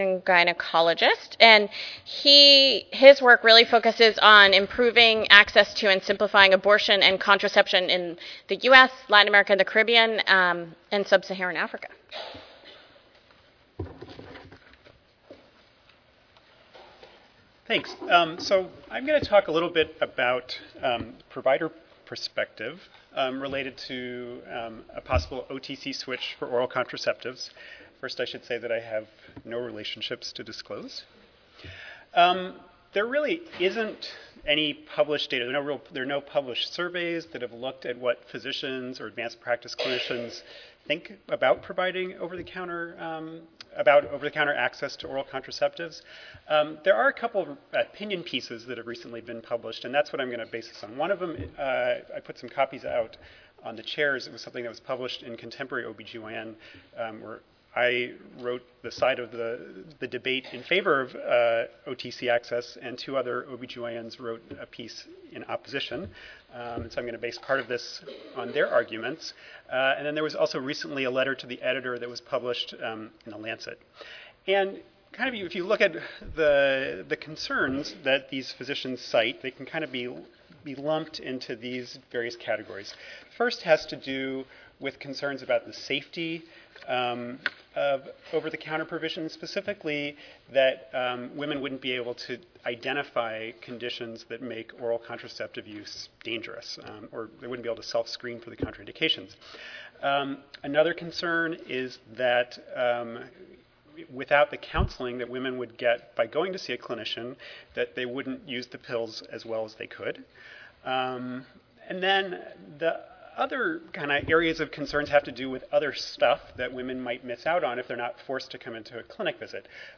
In this panel, we will review safety and effectiveness data, share information on clinician concerns and perspectives, discuss how to address the concerns of women�s groups and describe how low-income women could potentially be adversely affected by a switch.